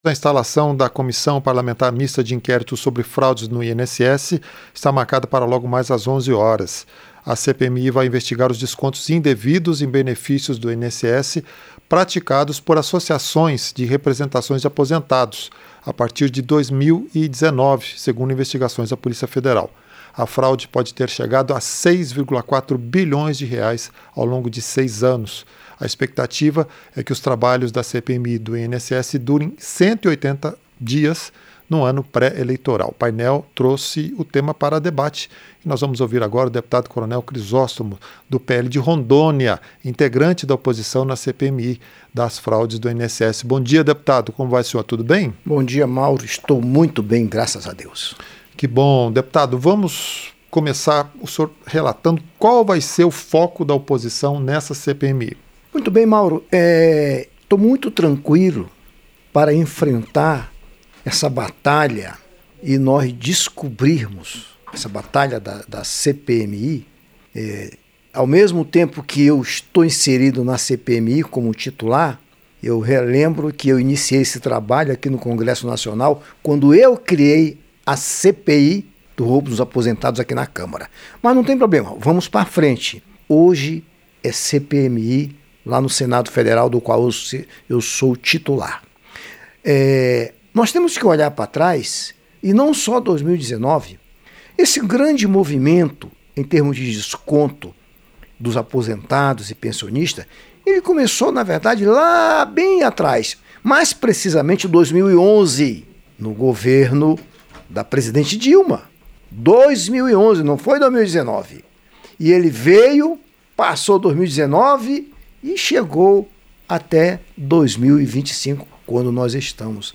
Entrevista - Dep. Coronel Chrisóstomo (PL-RO)